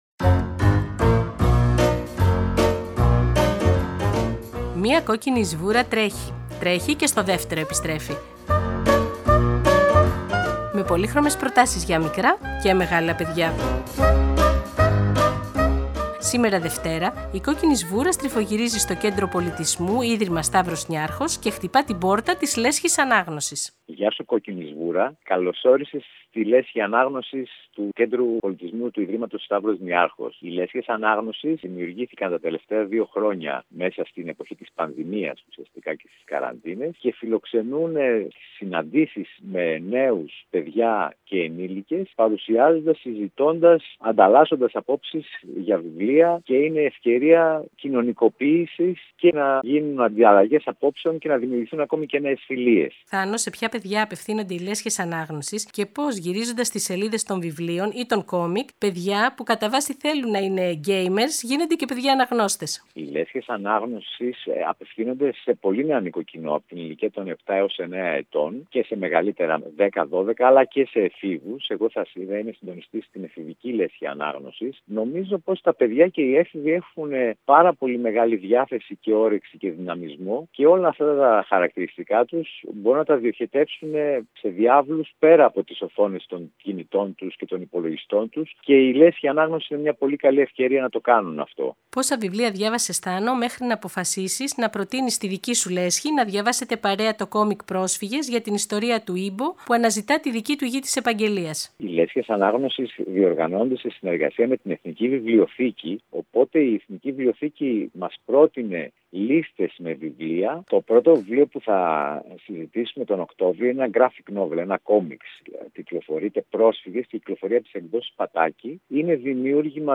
Σήμερα Δευτέρα, η Κόκκινη Σβούρα στριφογυρίζει στο Κέντρο Πολιτισμού Ίδρυμα Σταύρος Νιάρχος και γράφεται στη Λέσχη Ανάγνωσης.